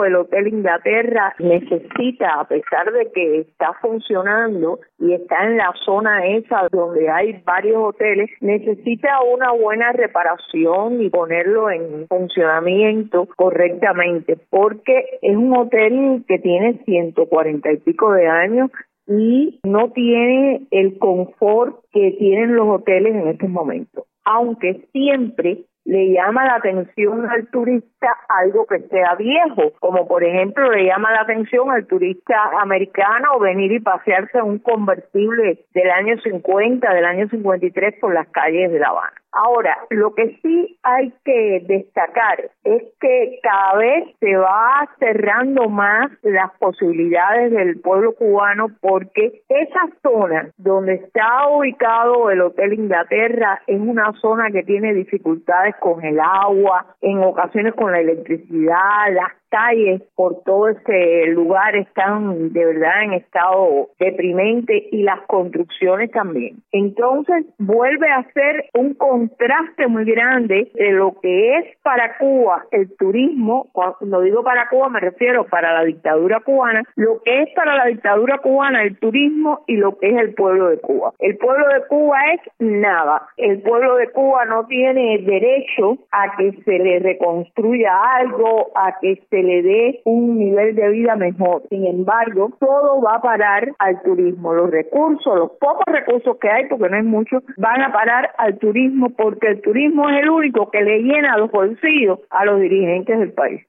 Hotel Inglaterra. Entrevista